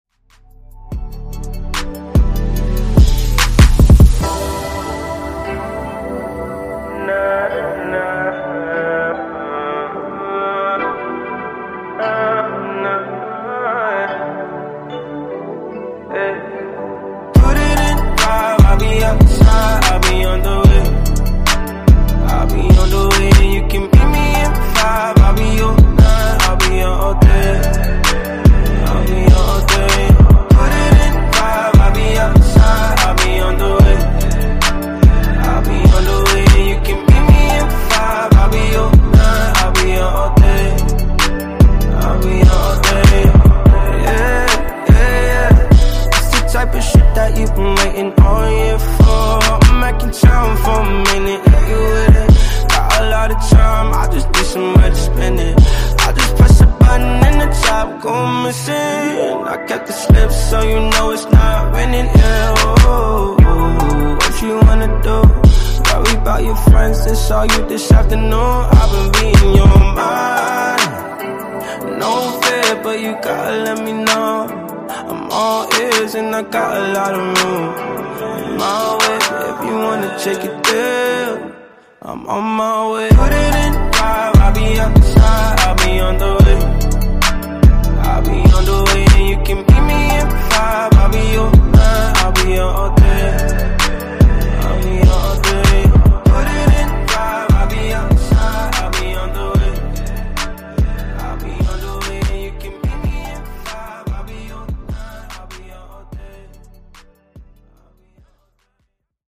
Genre: RE-DRUM Version: Dirty BPM